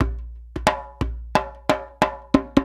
90 JEMBE8.wav